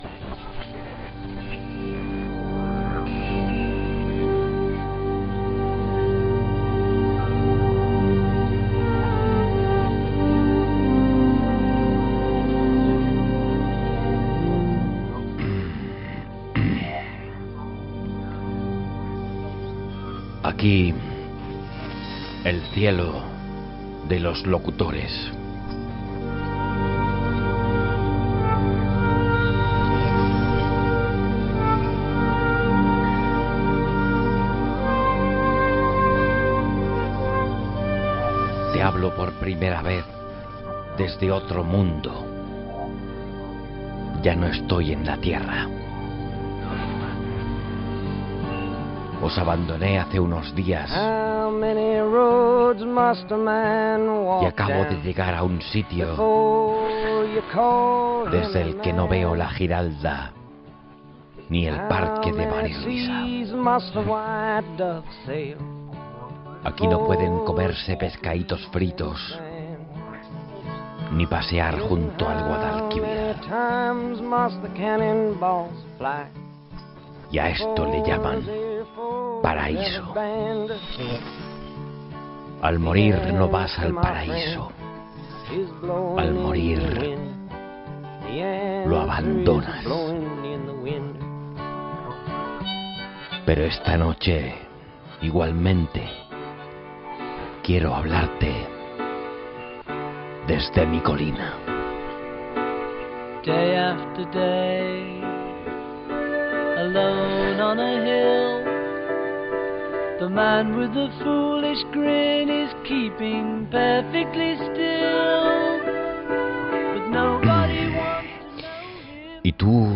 Espai fet seguint l'estil del presentador Jesús Quintero quan presentava el programa "El loco de la colina" . Al cel Quintero entrevista a una vella que va morir amb 106 anys feia 37 anys.